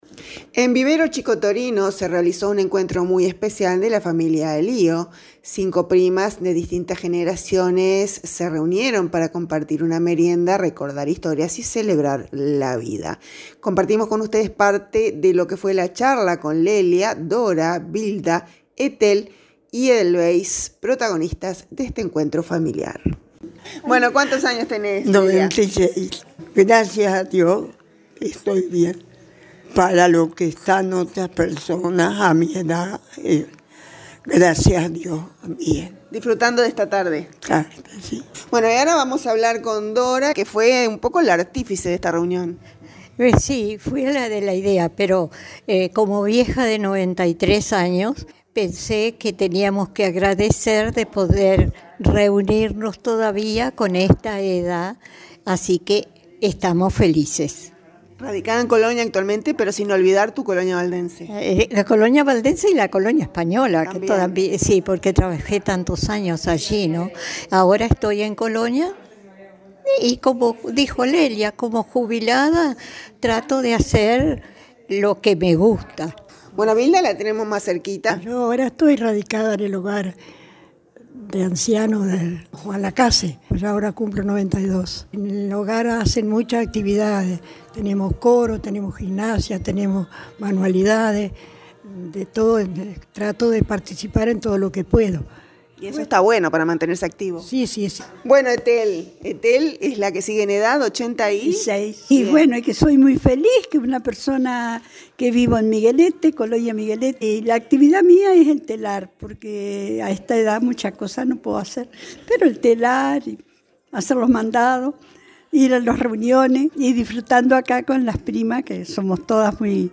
Durante la reunión, y en un ameno diálogo, cada una compartió con RO Contenidos cómo vive esta etapa de la vida y la alegría de poder reencontrarse después de un tiempo sin verse.